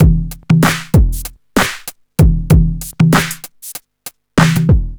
• 96 Bpm Breakbeat E Key.wav
Free drum beat - kick tuned to the E note. Loudest frequency: 1108Hz
96-bpm-breakbeat-e-key-Dxm.wav